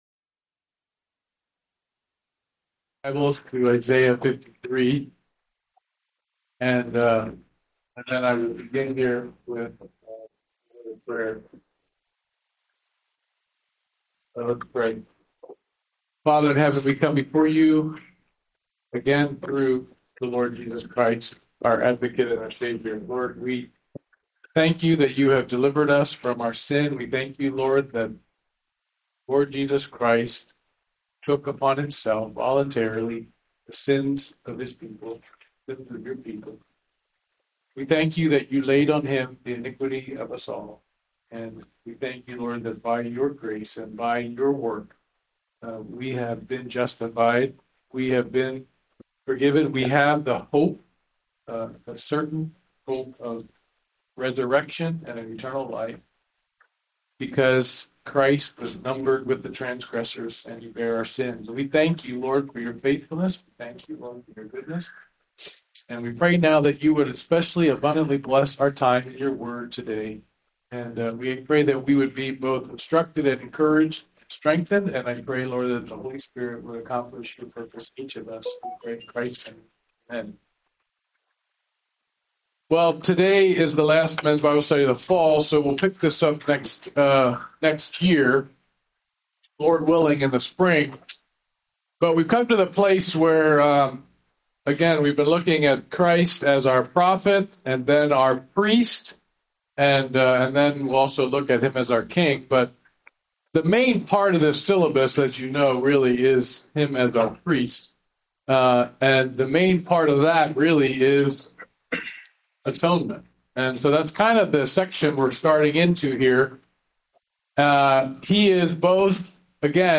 Service Type: Men's Bible Study